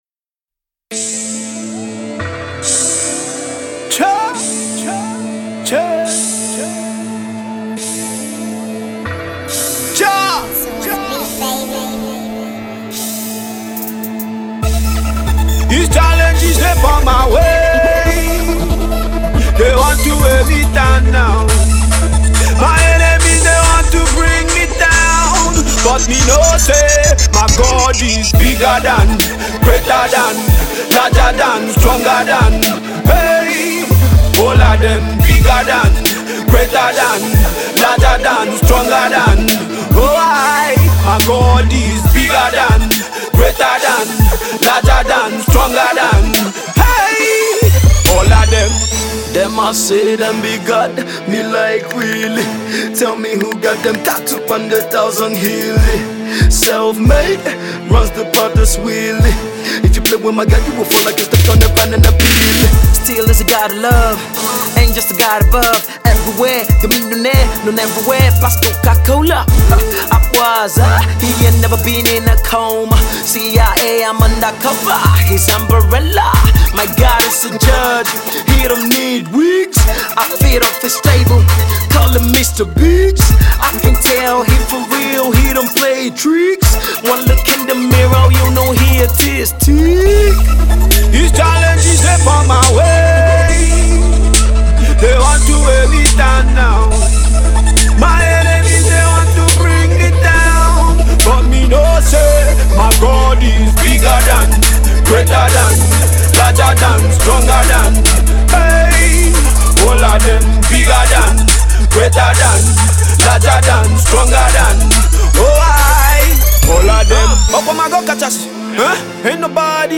Nigerian hip hop